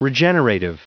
Prononciation du mot regenerative en anglais (fichier audio)
Prononciation du mot : regenerative